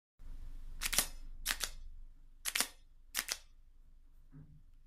As for the sound, the pico sounds a little bit wired, mostly because the majority of the music is given by the sliding of the flutes.
When a pico stands up